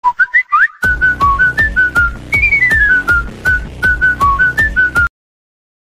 Category: Messages Ringtones